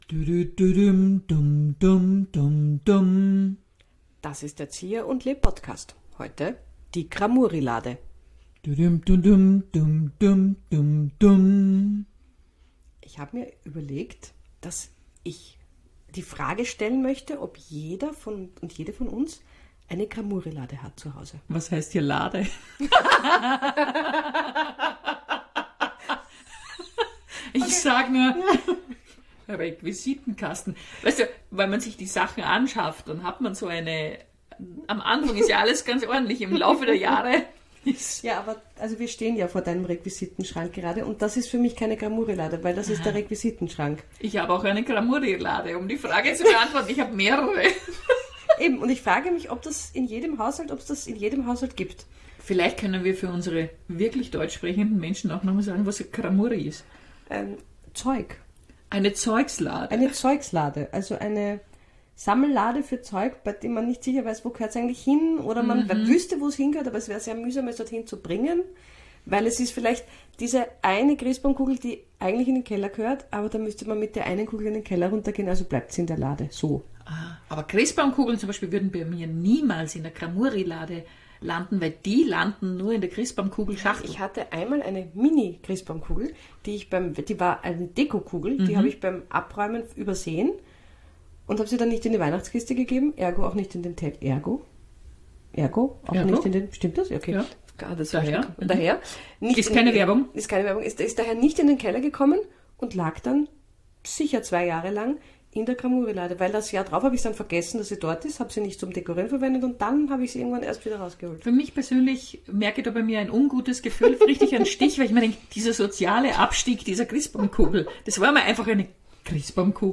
Wir machen Improkabarett aus Leidenschaft und spielen seit über 17 Jahren miteinander.